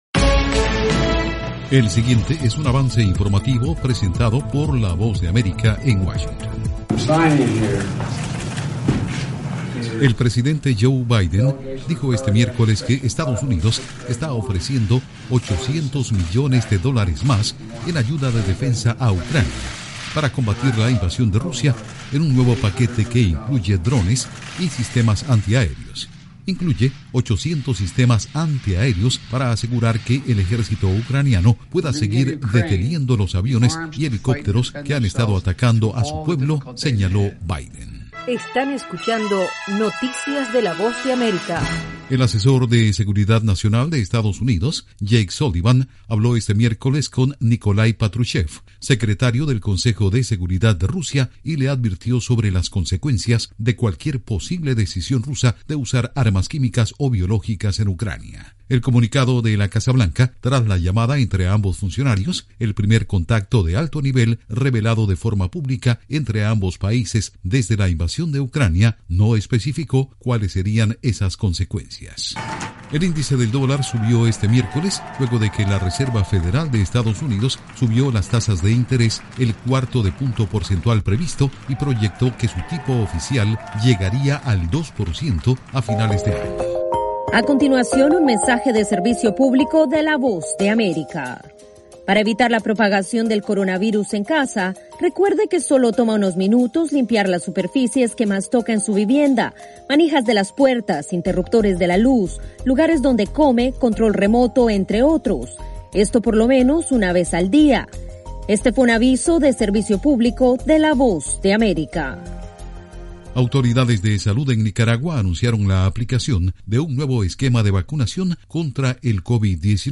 El siguiente es un avance informativo presentado por la Voz de América en Washington.